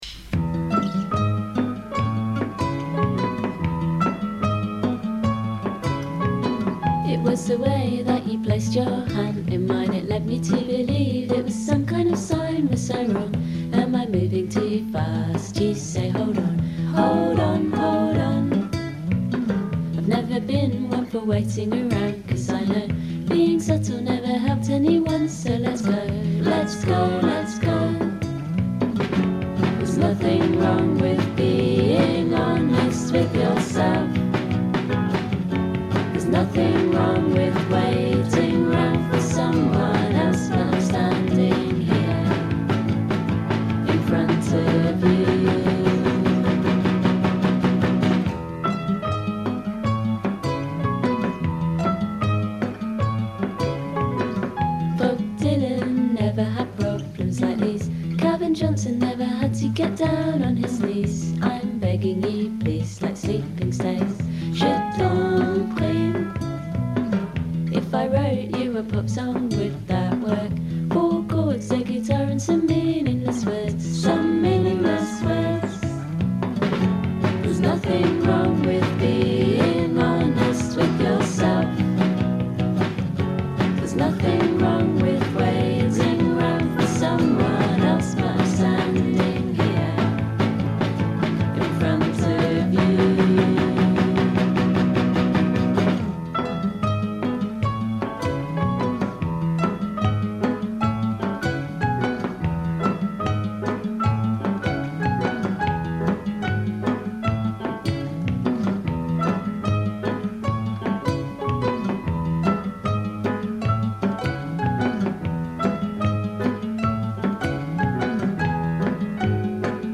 La voce un po' nasale
il fruscio della registrazione fatta in casa